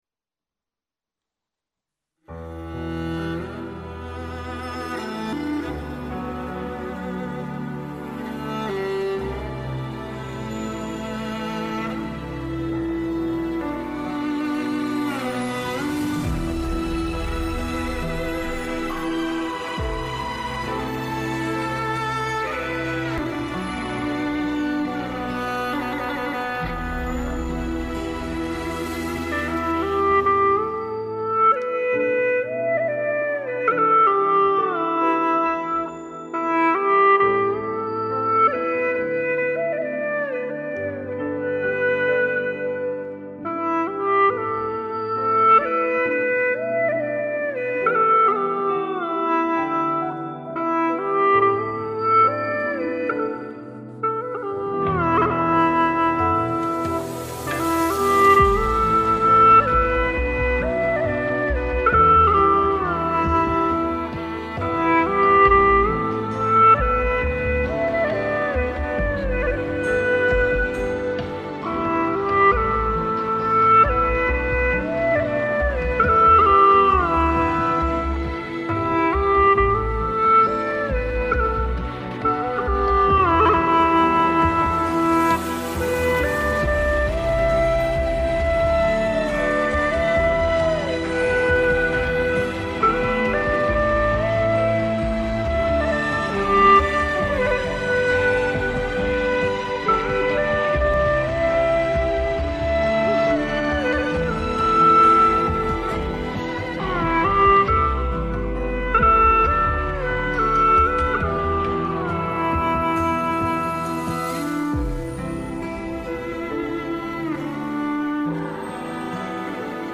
调式 : G 曲类 : 影视